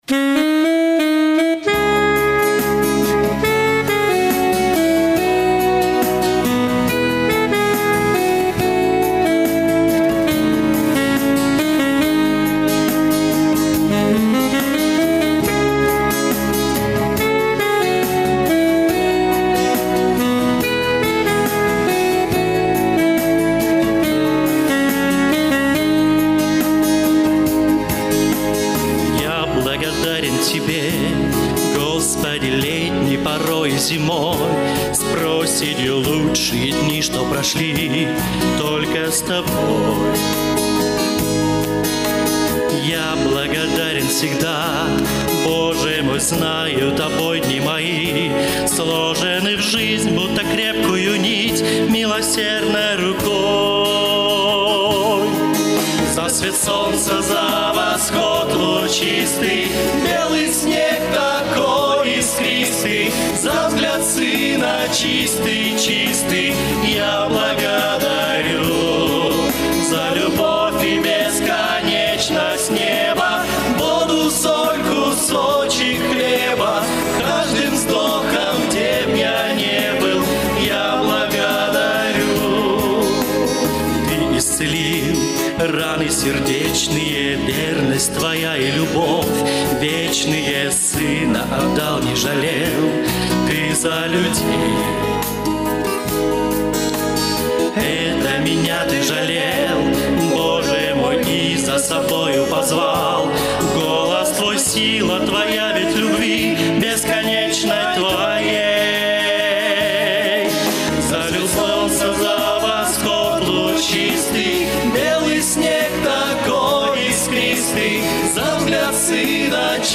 Богослужение 11.02.2024
За свет солнца, за восход лучистый - Братья (Пение)[